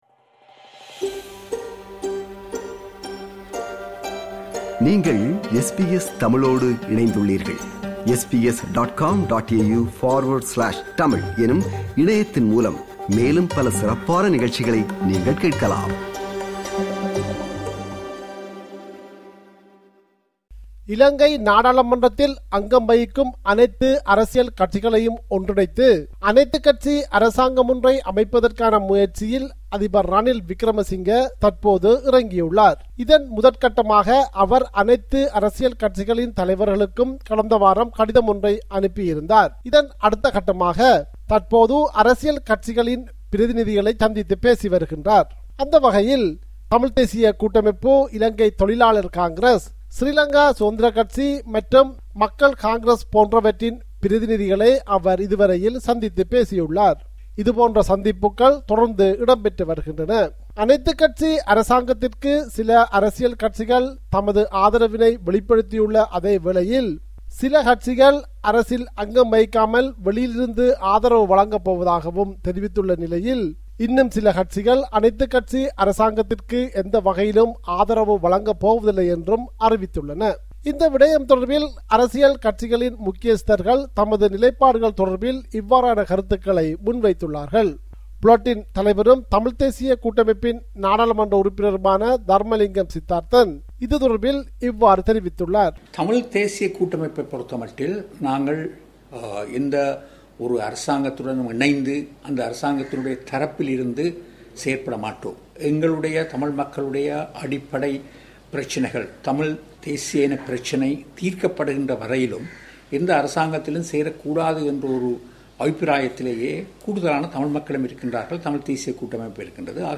compiled a report focusing on major events/news in North & East / Sri Lanka.